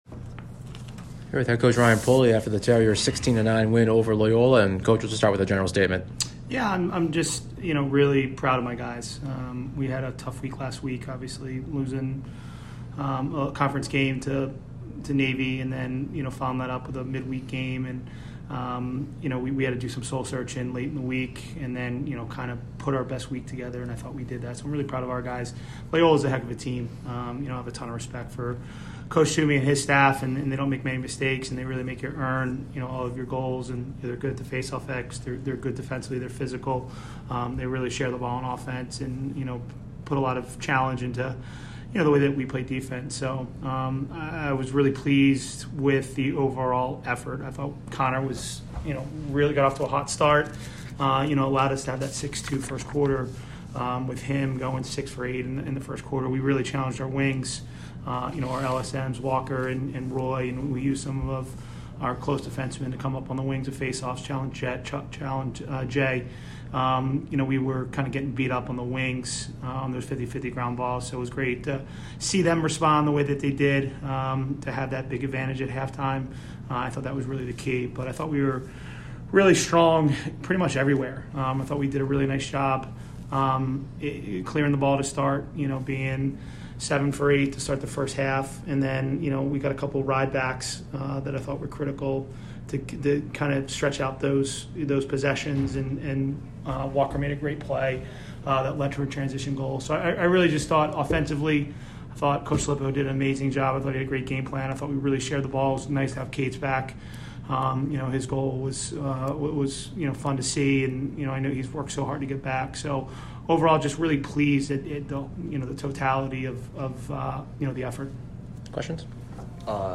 Loyola Maryland Postgame Interview